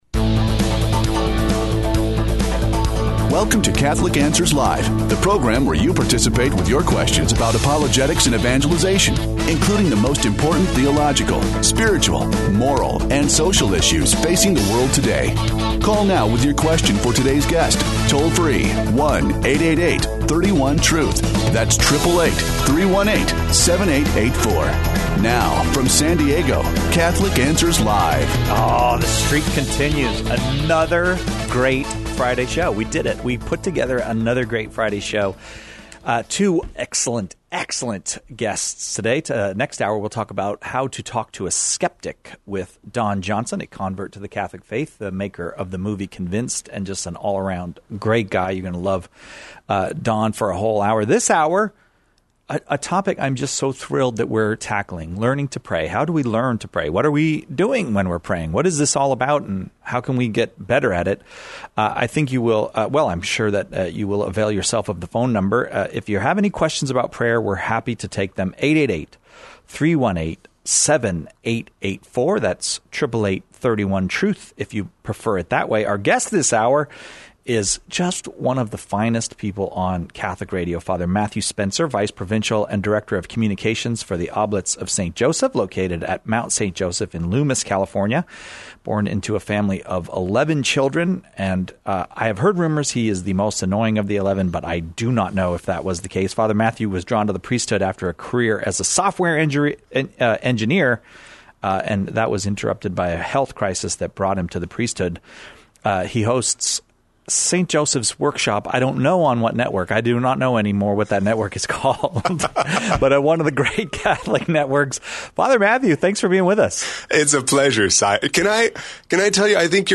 shares tips with listeners seeking help with their prayer lives.